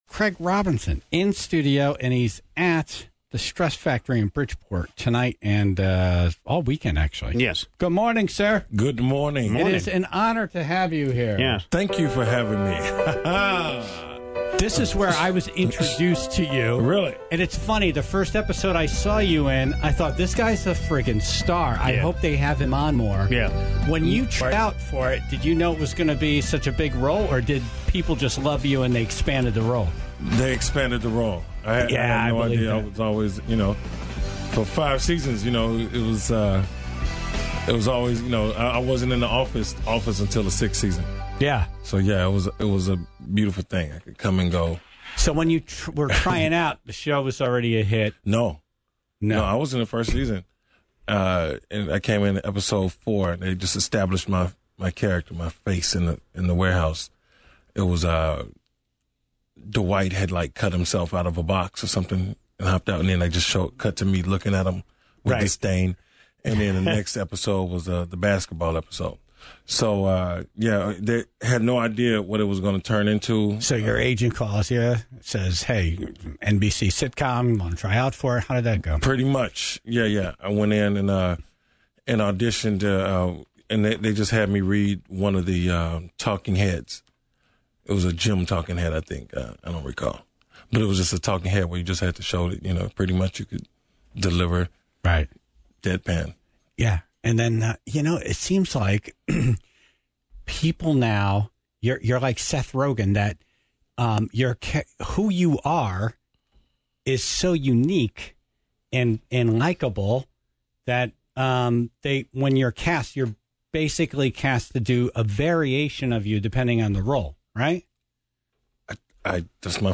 Craig Robinson Live In Studio